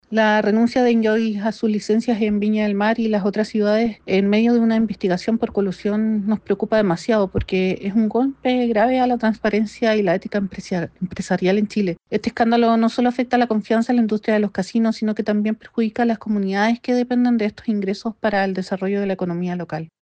Asimismo, Nancy Díaz, edil e integrante de la Comisión de Turismo y Desarrollo Económico, acusó que la renuncia de Enjoy, en medio de una investigación por colusión, representa un golpe grave a la transparencia y a la ética empresarial.